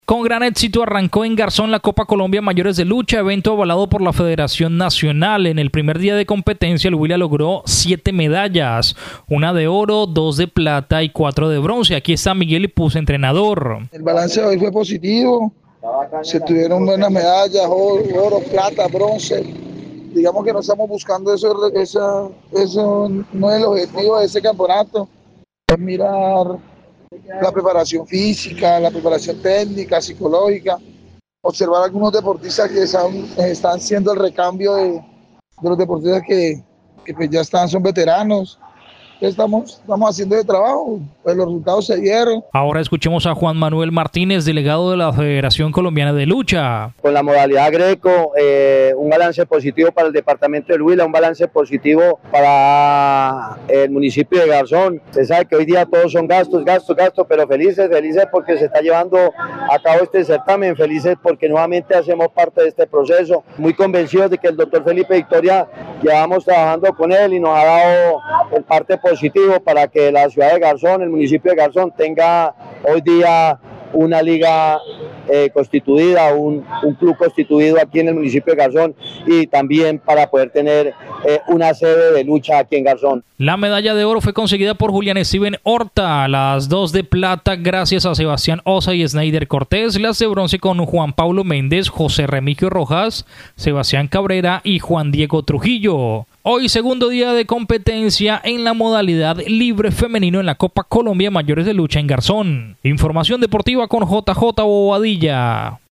VOZ_TITULAR_DEPORTES_15_AGOSTO.mp3